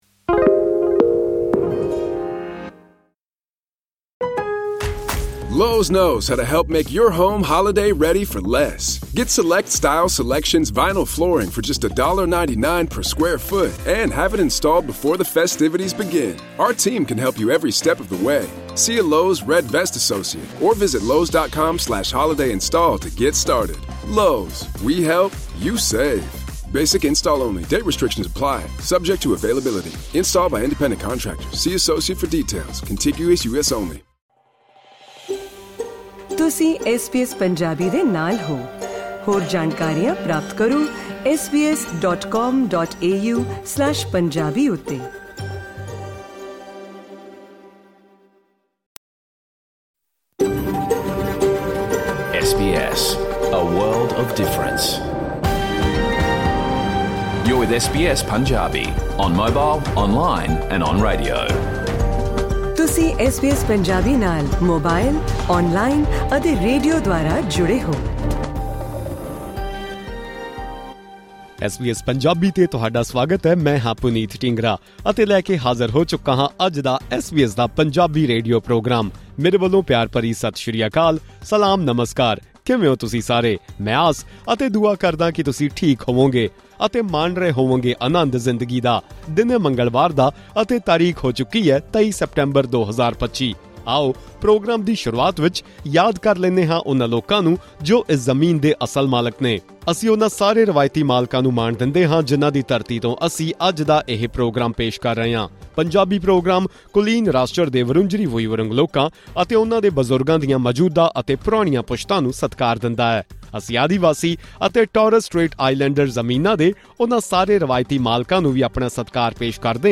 ਸੁਣੋ ਐਸ ਬੀ ਐਸ ਪੰਜਾਬੀ ਦਾ ਪੂਰਾ ਰੇਡੀਓ ਪ੍ਰੋਗਰਾਮ
In this SBS Punjabi radio program, you will be able to listen to Australia’s national anthem in Punjabi and also understand the meaning of its lyrics. In addition, the show features Australian and international news, along with updates from both East and West Punjab.